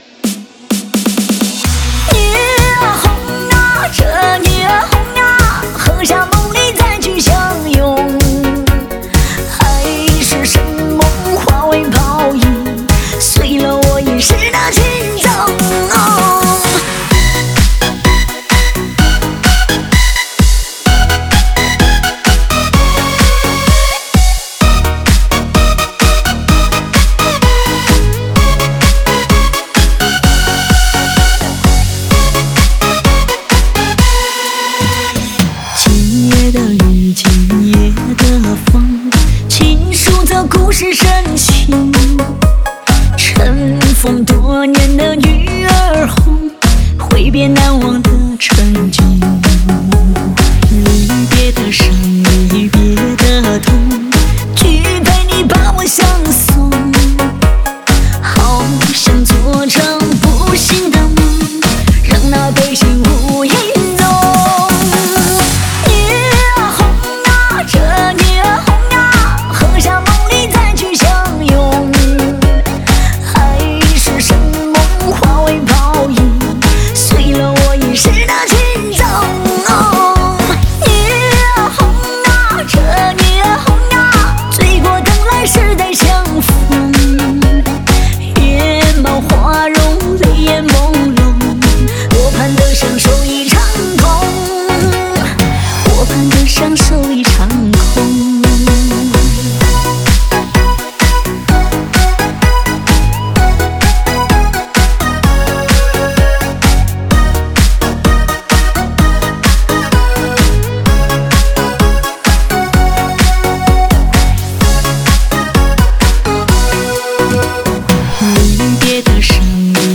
这首歌曲音质不错